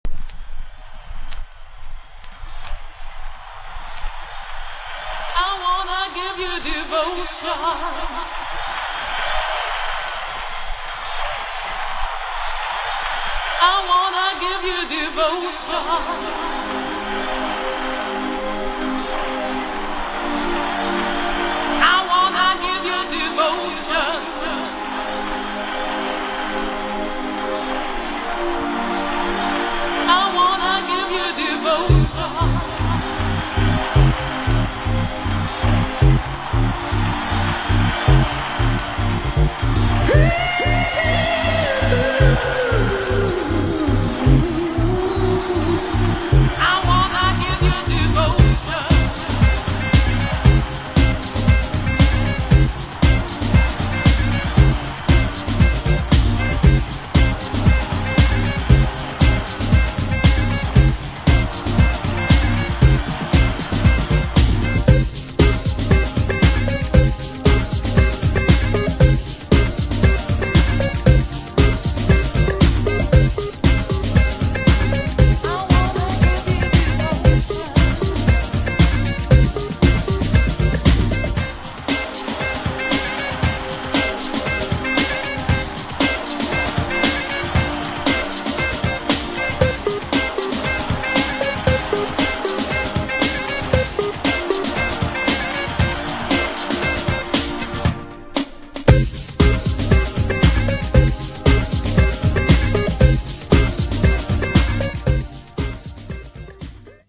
INSTも使える!
GENRE House
BPM 116〜120BPM